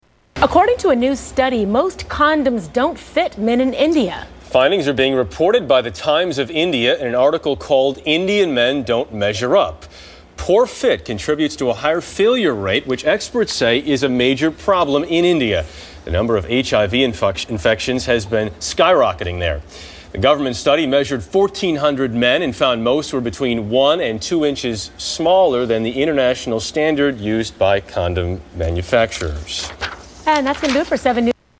Tags: Media Freudian Slips News Newscasters Funny